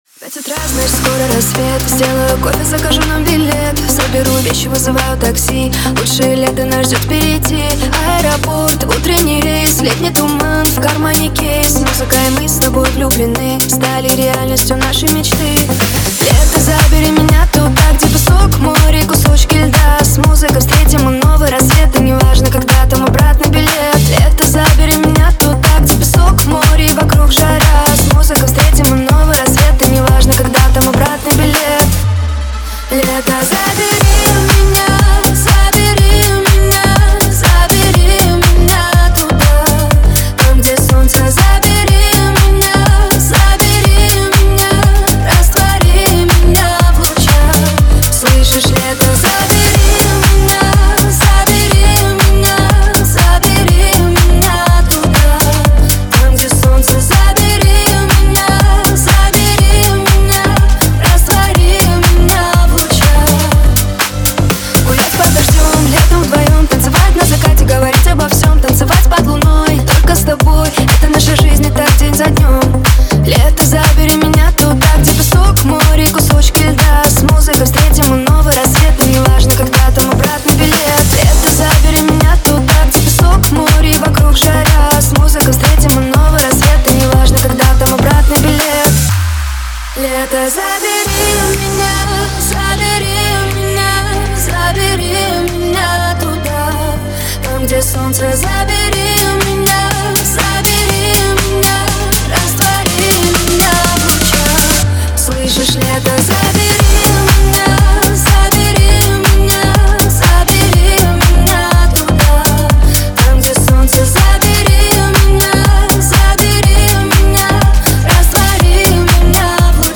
Супер ремикс от промо диджей